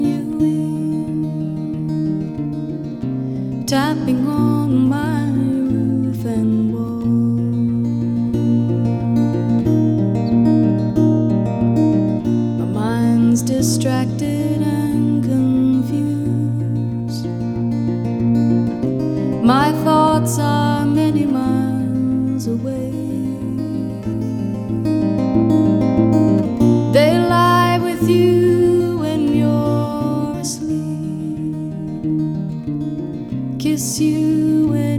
Contemporary Singer Songwriter Rock Adult Alternative
Жанр: Рок / Альтернатива